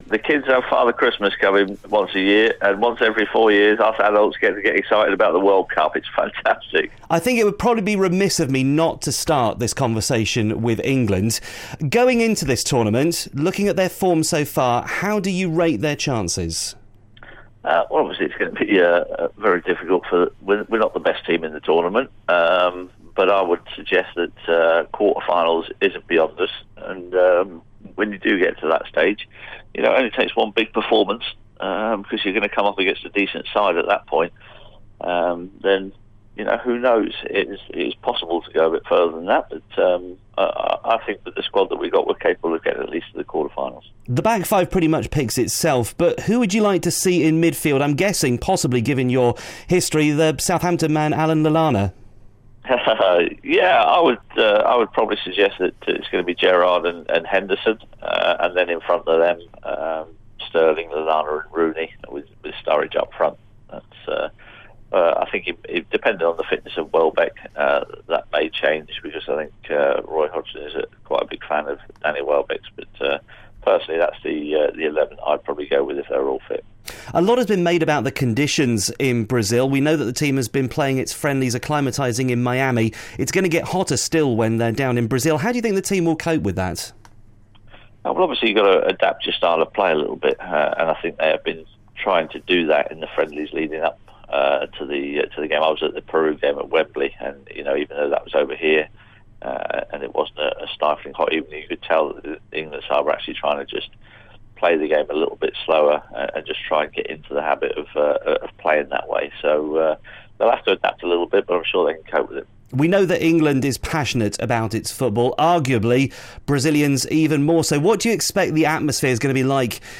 Local footballing legend Matt le Tissier speaks